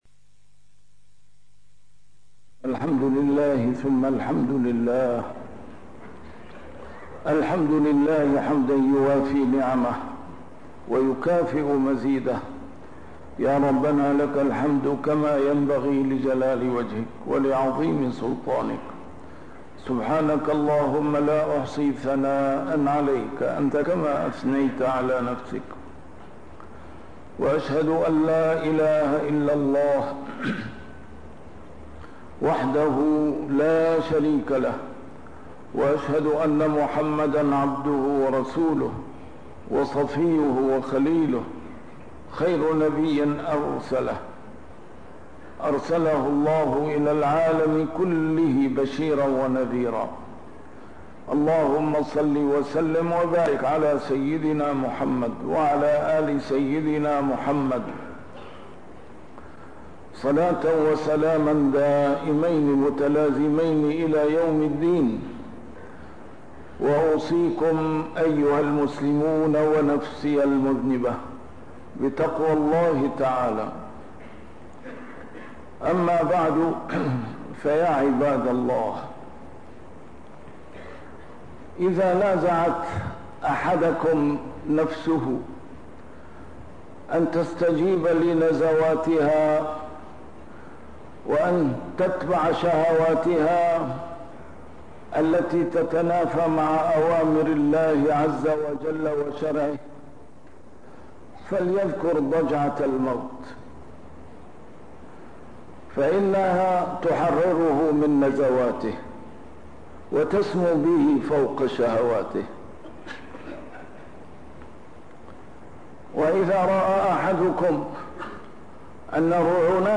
A MARTYR SCHOLAR: IMAM MUHAMMAD SAEED RAMADAN AL-BOUTI - الخطب - حديث عن الموت